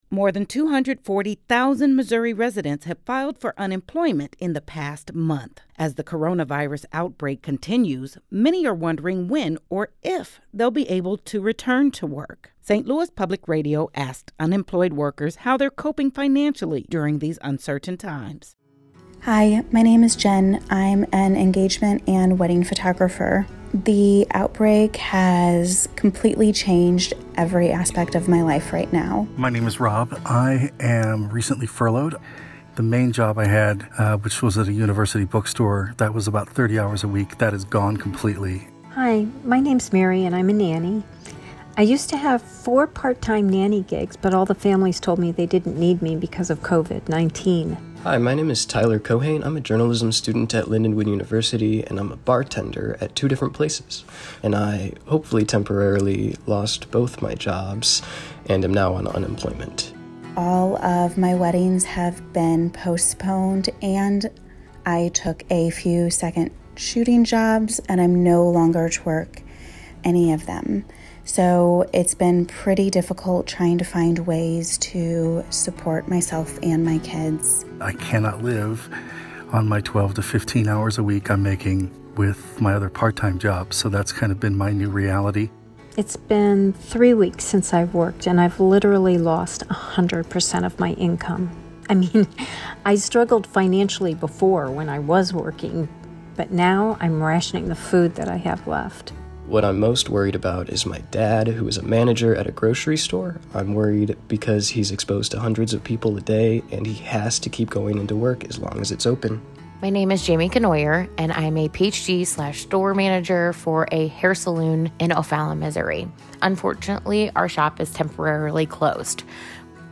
Unemployed workers in St. Louis submitted "audio diaries" of their experiences during the coronavirus pandemic.